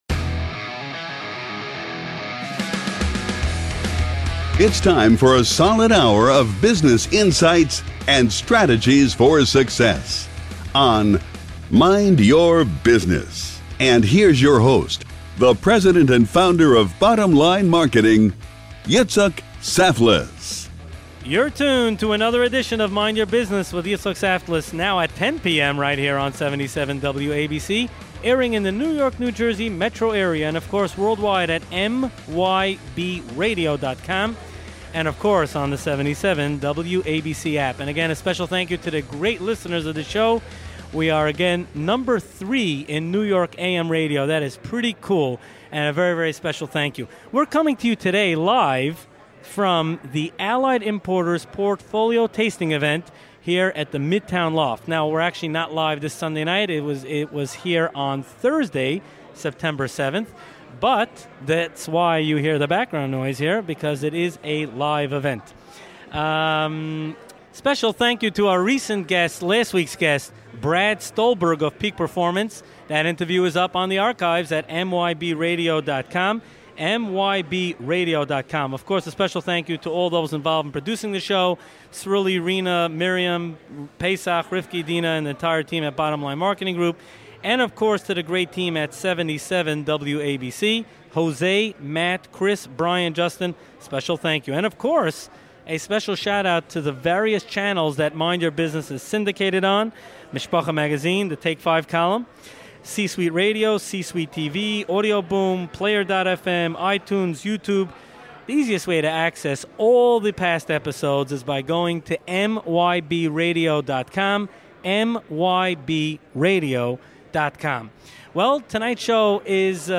Episode 107: Every Hour, Happy Hour! Recorded Live at Allied Importers’ Portfolio Event